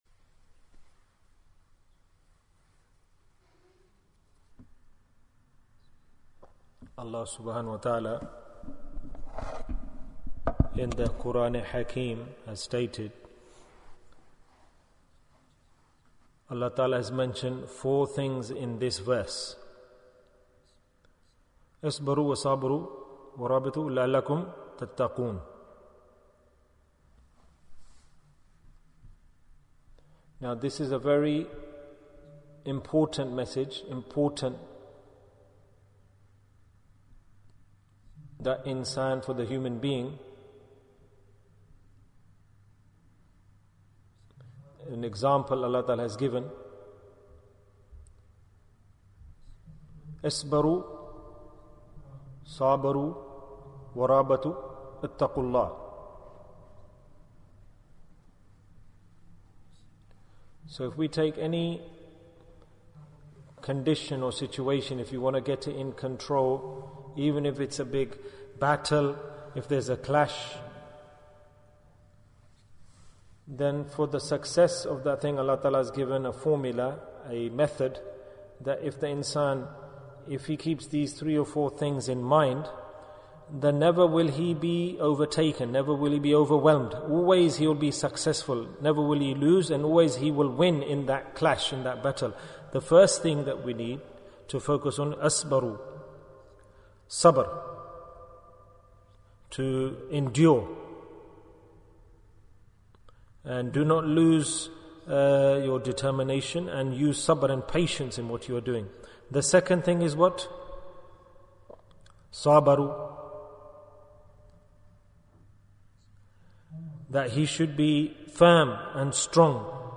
After Ramadhan Bayan, 61 minutes28th April, 2022